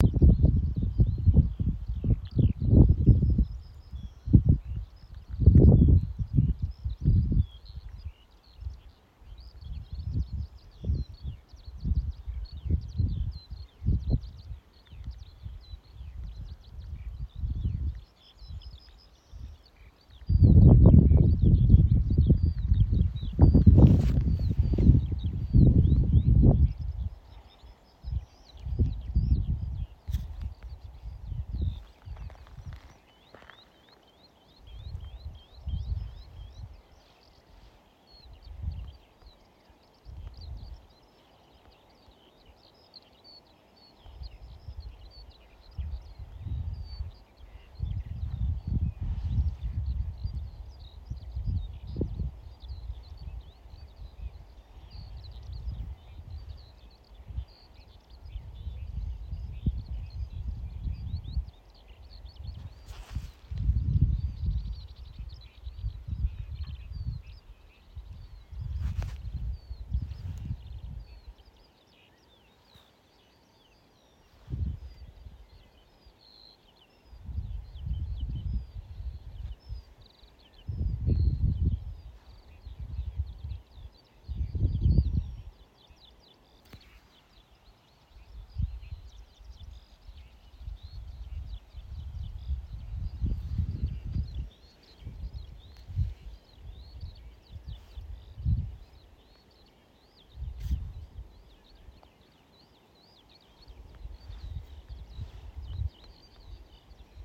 Red-backed Shrike, Lanius collurio
Administratīvā teritorijaCarnikavas novads
StatusVoice, calls heard